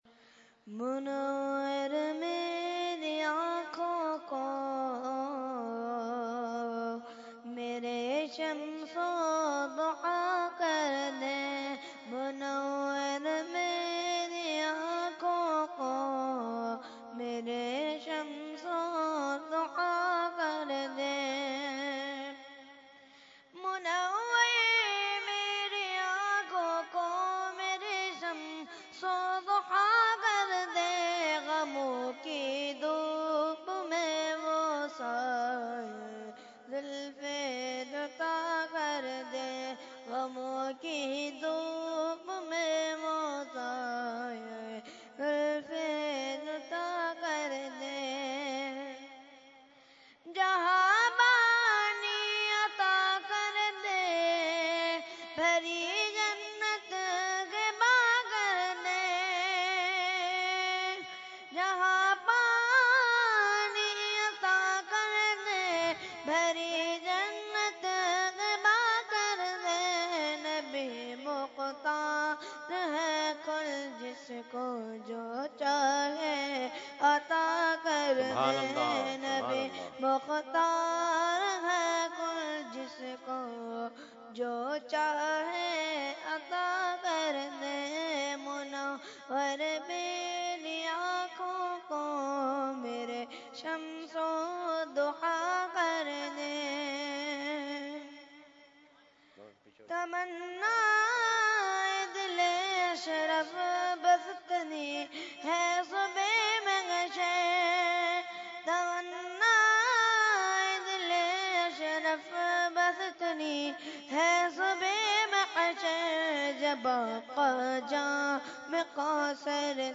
Mediaa: Urs Makhdoome Samnani 2016
Category : Naat | Language : UrduEvent : Urs Makhdoome Samnani 2016